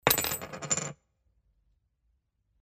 SFX – COIN – A
SFX-COIN-A.mp3